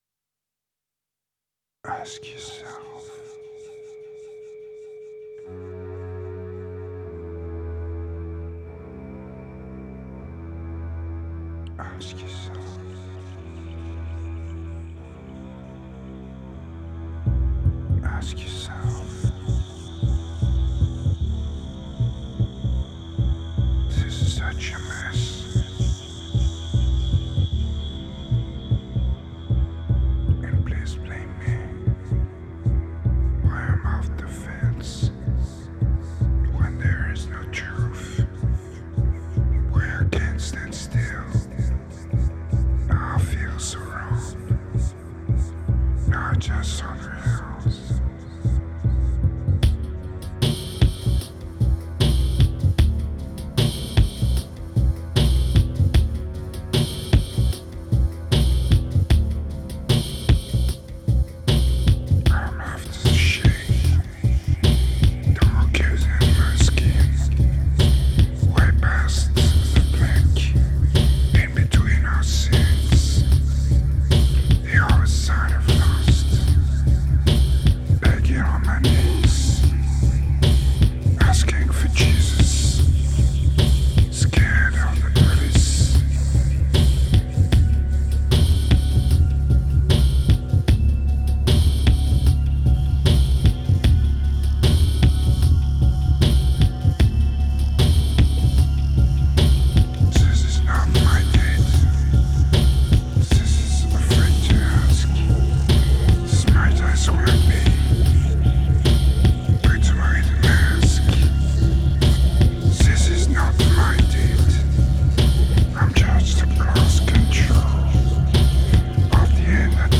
Pure remote desire, strong medication and raw electronica.